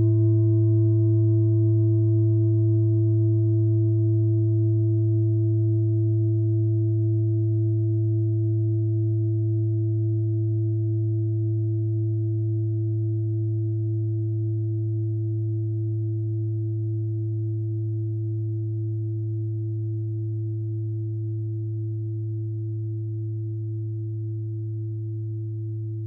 Klangschale TIBET Nr.36
Sie ist neu und ist gezielt nach altem 7-Metalle-Rezept in Handarbeit gezogen und gehämmert worden.
(Ermittelt mit dem Filzklöppel)
Der Venuston liegt bei 221,23 Herz und damit nahe beim "A" unserer Tonleiter.
klangschale-tibet-36.wav